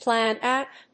アクセントplán óut